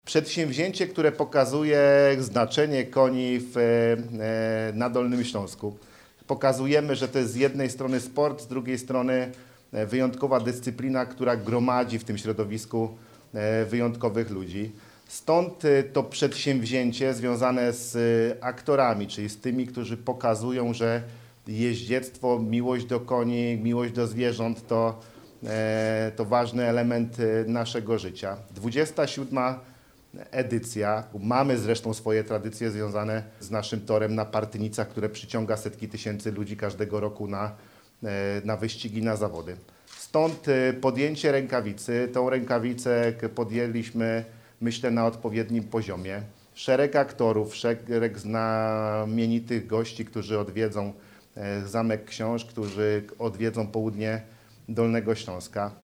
Marszałek Województwa Dolnośląskiego, Paweł Gancarz, podkreślił, że Dolny Śląsk ma silne tradycje jeździeckie, które warto rozwijać.